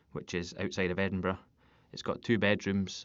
Edinburgh (Scottish) English